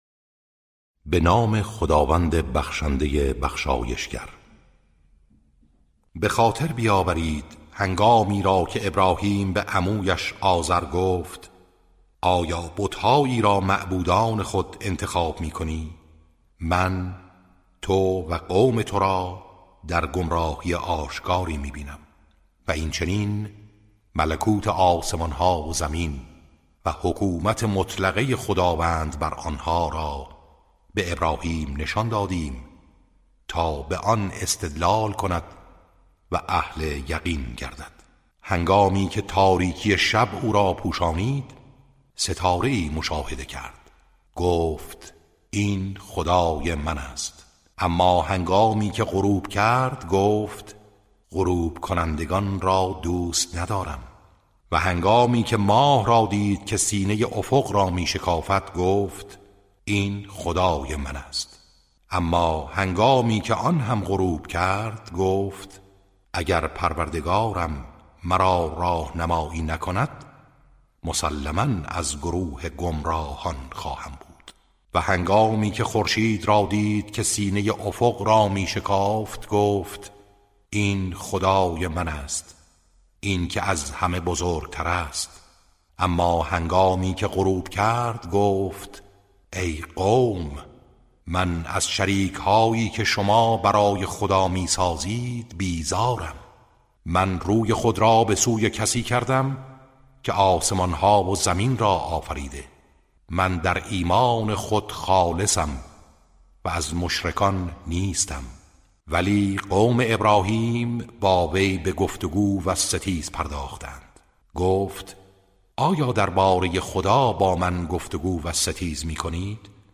ترتیل صفحه 137 از سوره انعام(جزء هفتم)